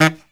HIHITSAX06-R.wav